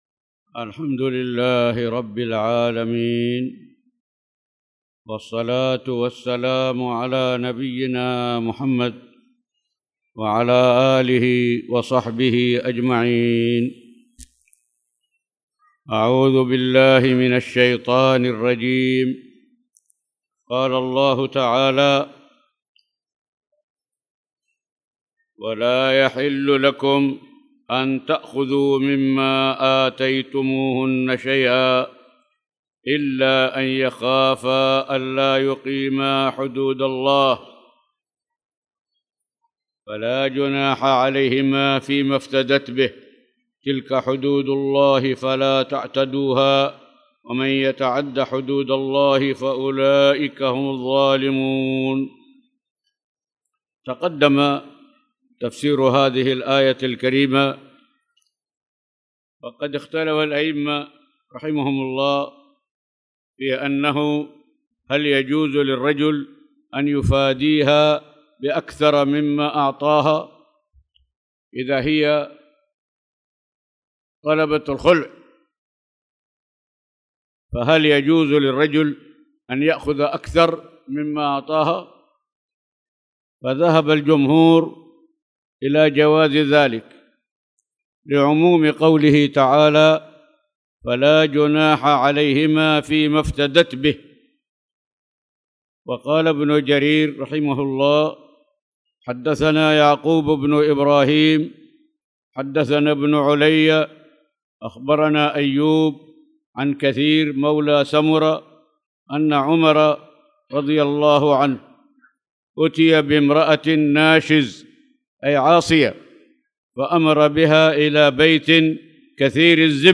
تاريخ النشر ٣٠ ربيع الثاني ١٤٣٨ هـ المكان: المسجد الحرام الشيخ